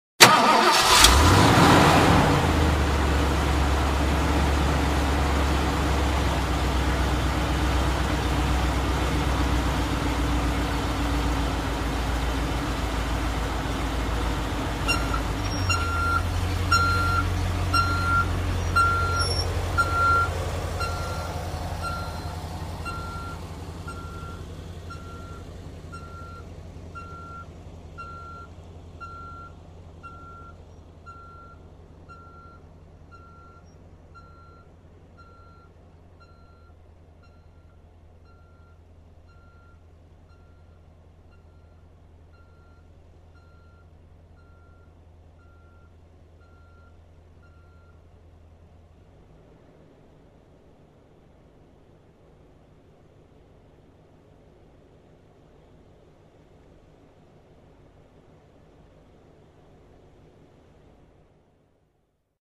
Звуки заднего хода
Громкий рев мотора при движении большегруза задним ходом